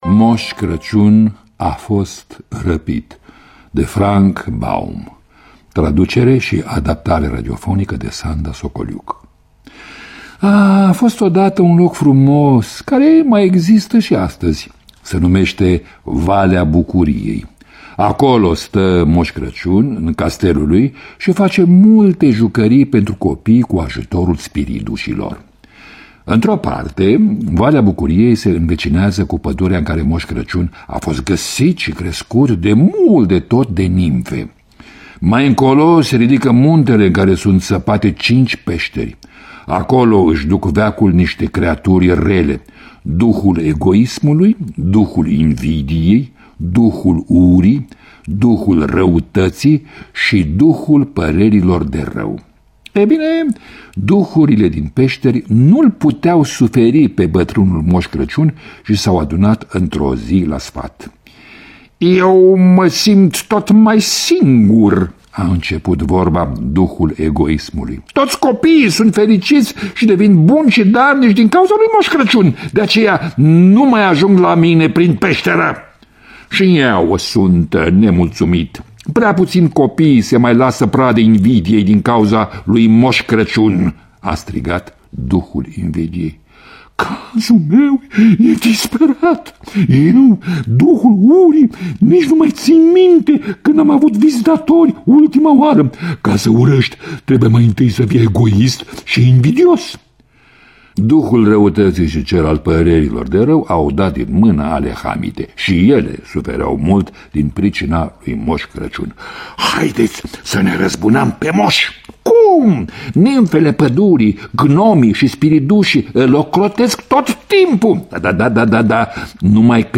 Lectura: Mircea Albulescu.